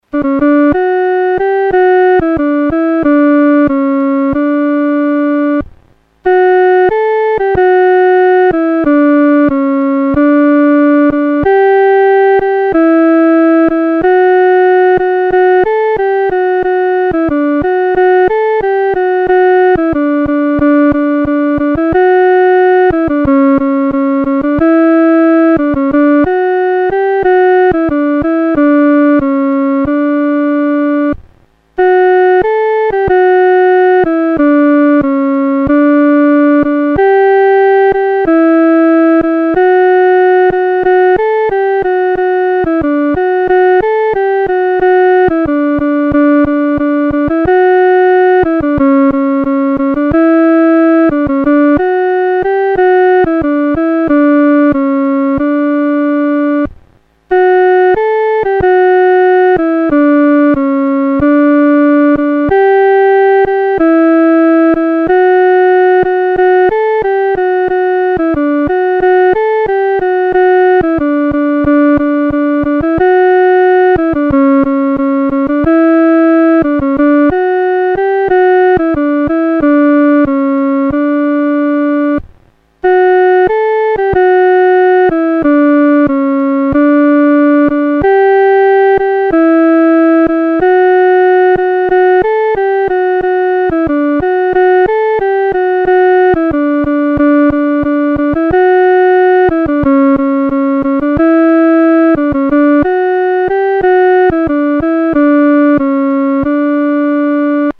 独奏（第二声）
普世欢腾-独奏（第二声）.mp3